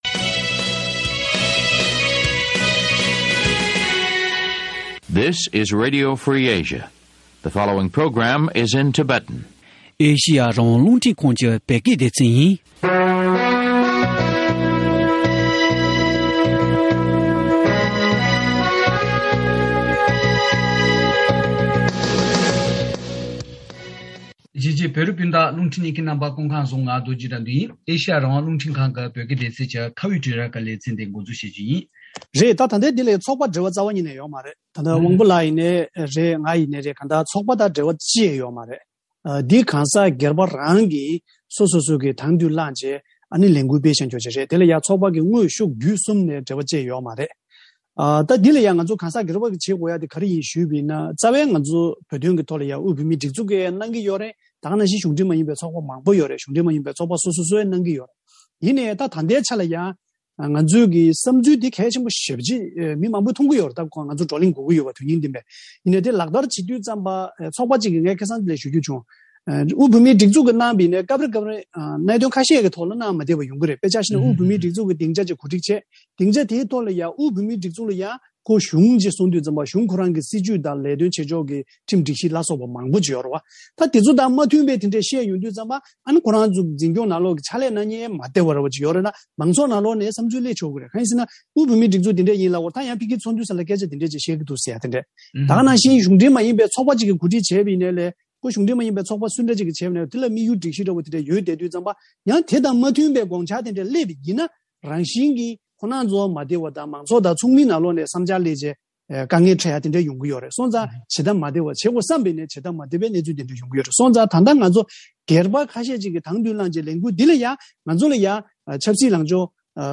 བོད་ཀྱི་མ་འོངས་པའི་མདུན་ལམ་འཚོལ་ཞིབ་ཅེས་པའི་བགྲོ་གླེང་དང་བགྲོ་གླེང་ཐོག་བྱུང་བའི་བསམ་ཚུལ་ལ་མཇུག་སྐྱོང་གནང་ཕྱོགས་ཐད་གླེང་མོལ་ཞུས་པ།